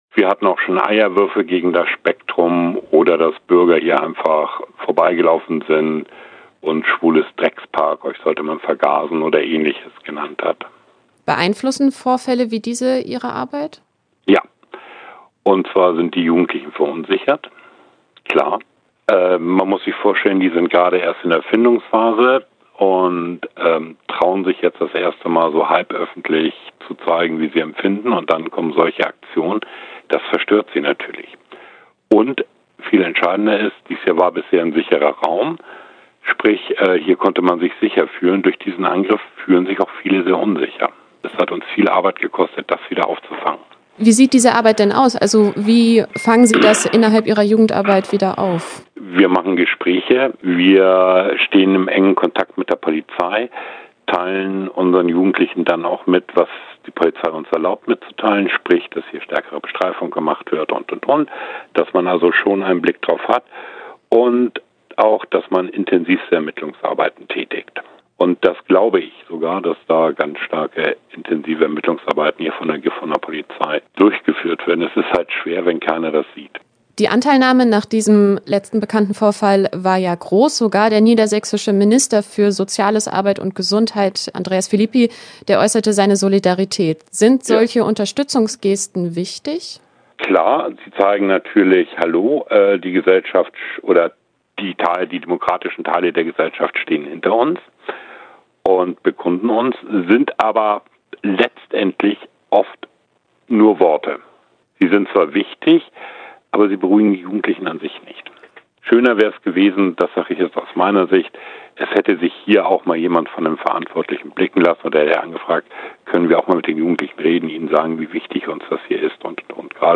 Interview-Queeres-Netzwerk-GF.mp3